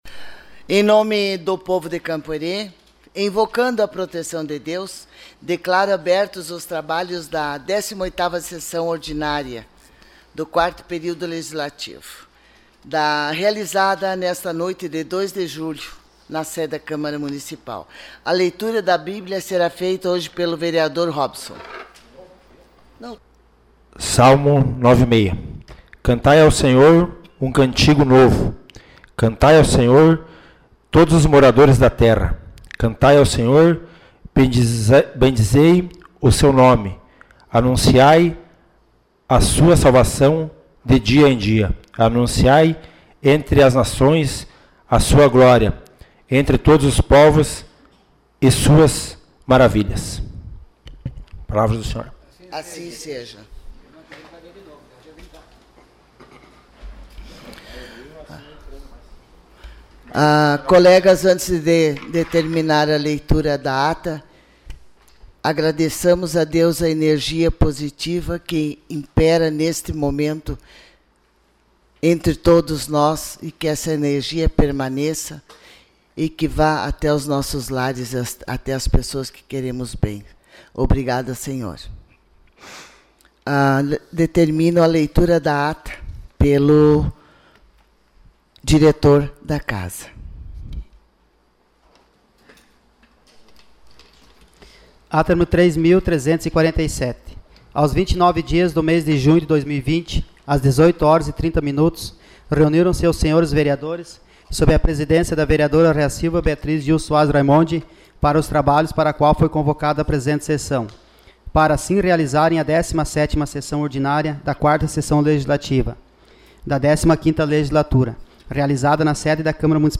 Sessão Ordinária dia 02 de julho de 2020